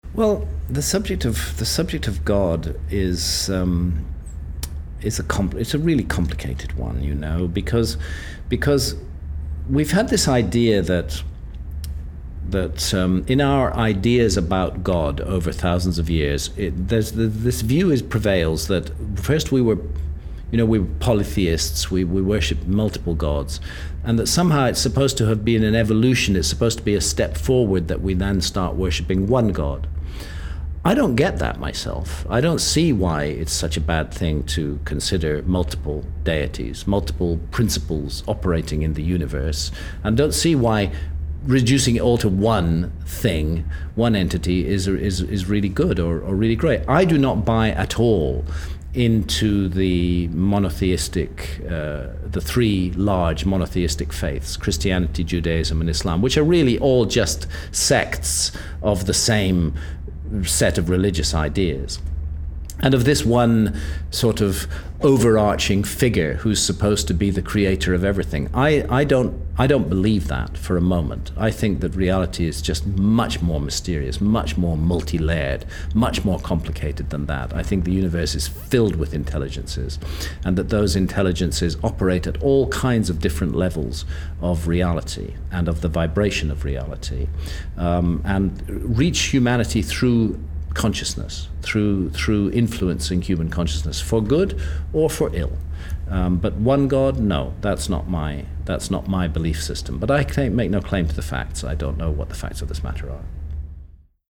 INTERVIEW: Graham Hancock Speaks Out
Then as the chairs were being folded and with only minutes before leaving for the airport, he graciously agreed to sit down with me and field a few questions: